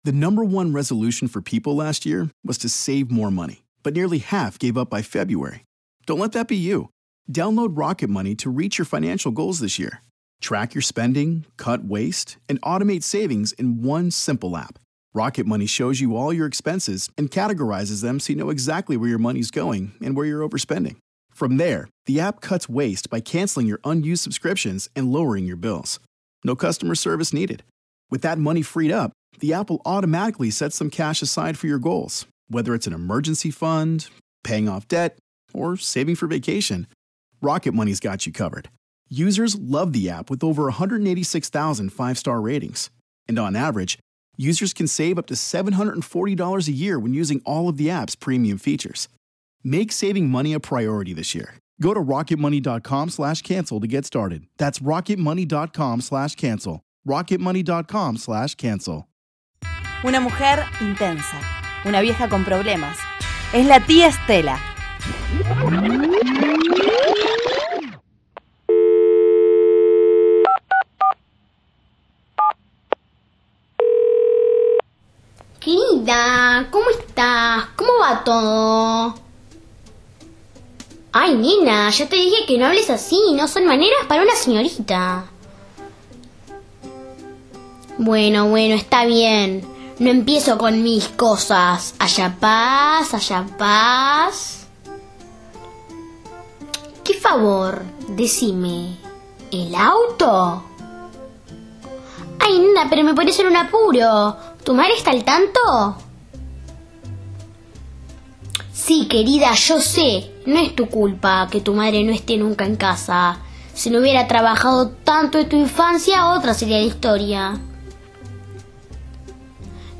Se emite por Radio Sur FM 88.3